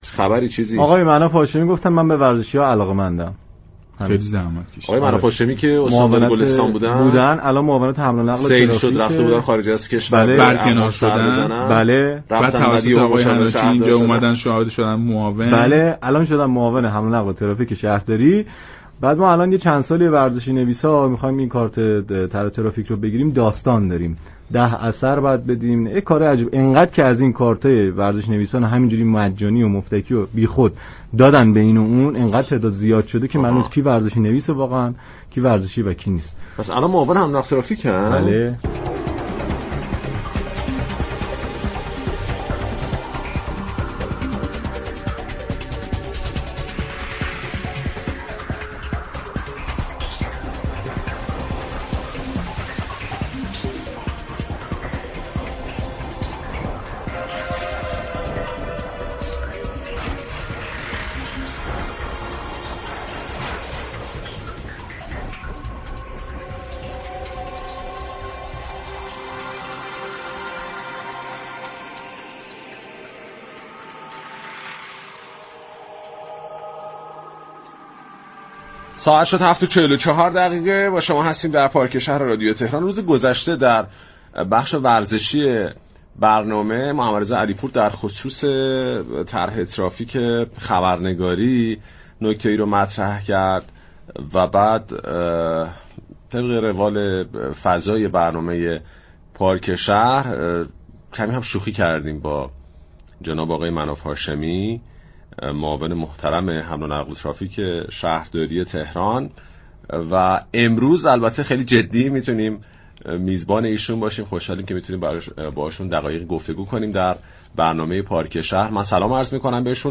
سیدمناف هاشمی معاون حمل ونقل و ترافیك شهرداری تهران در گفتگو با برنامه پارك شهر